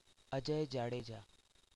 pronunciation born 1 February 1971), known as Ajay Jadeja, is an Indian former professional cricketer.